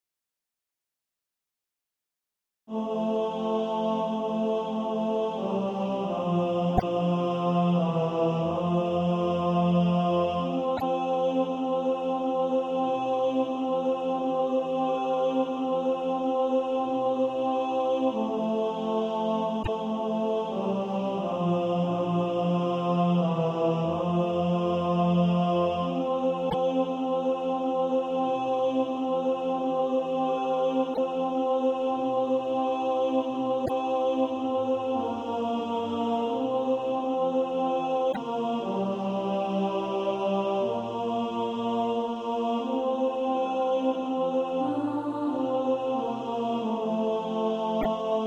(SATB) Author
Tenor Track.